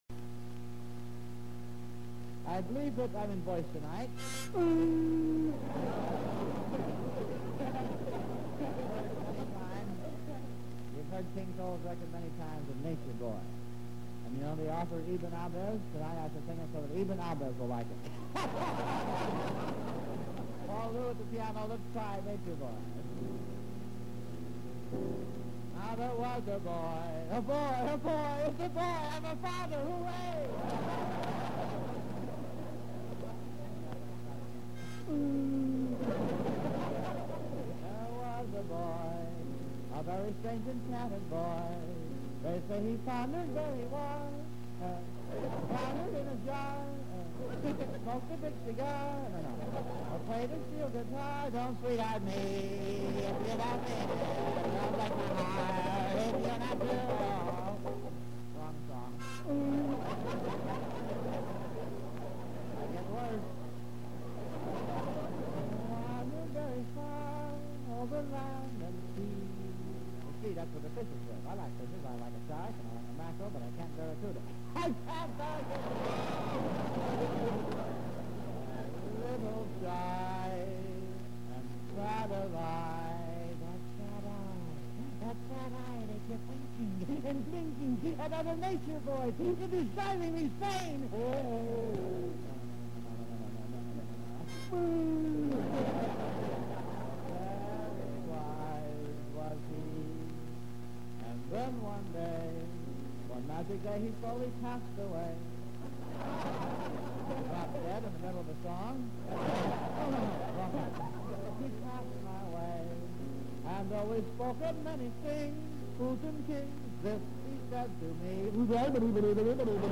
Zany Music Comedian